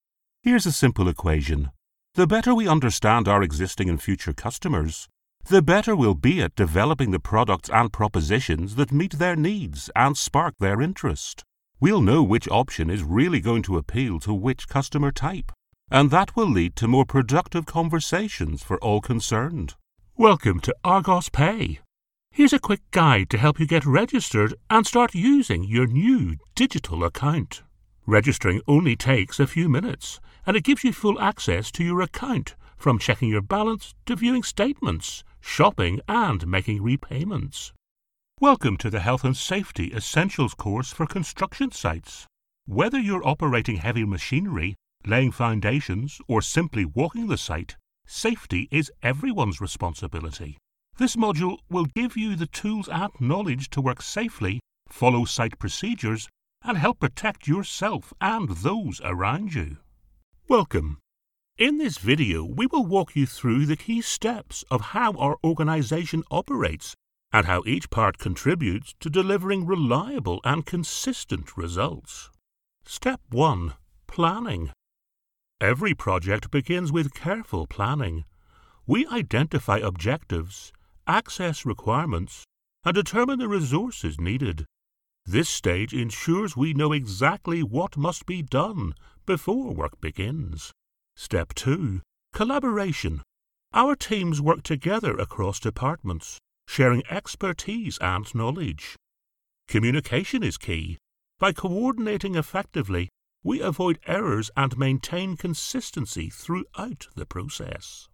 Male Voice Over Talent, Artists & Actors
English (Irish)
Adult (30-50) | Older Sound (50+)